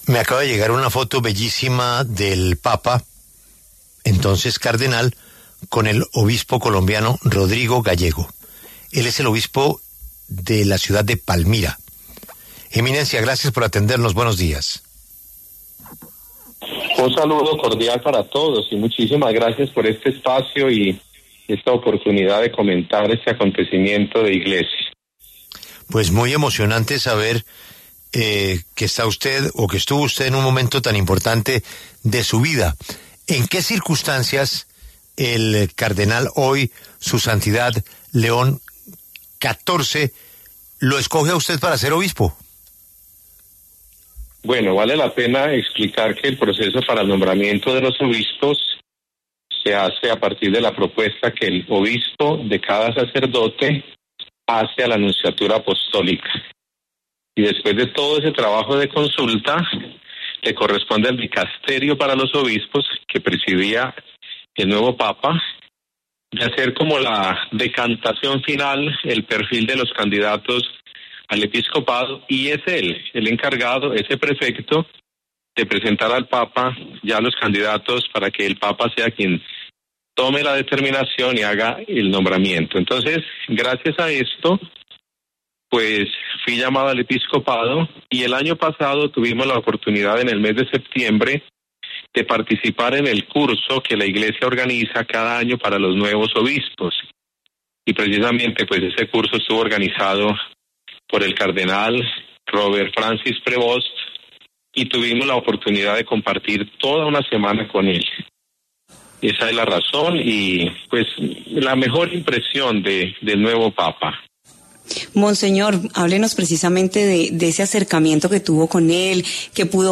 El obispo de Palmira, Rodrigo Gallego, conversó con La W sobre las ocasiones en las que compartió con el entonces cardenal Robert Prevost.